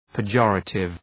{pı’dʒɔ:rətıv}
pejorative.mp3